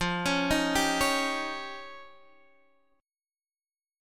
F7sus2#5 chord